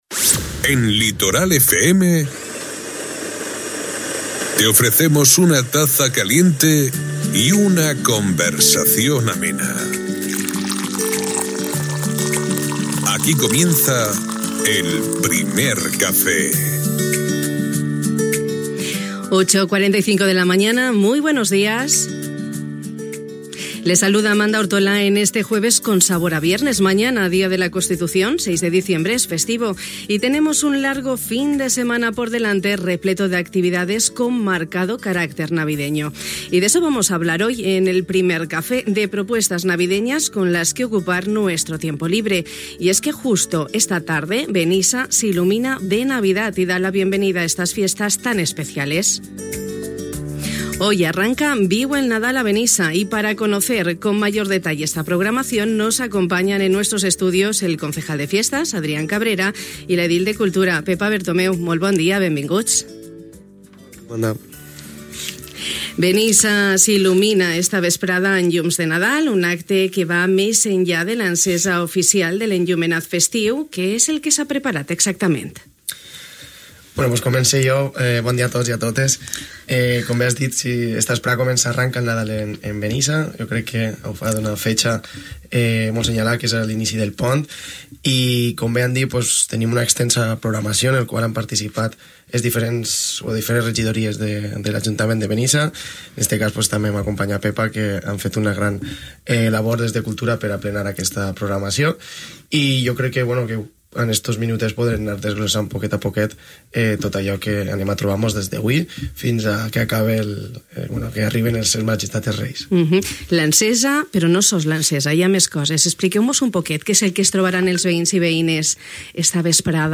Hui arrenca “Viu el Nadal a Benissa”, una extensa i variada programació lúdica, cultural, comercial, esportiva i sobretot festiva, que hem conegut amb detall al Primer Café de Ràdio Litoral en companyia del regidor de Festes, Adrián Cabrera, i de l’edil de Cultura, Pepa Bertomeu.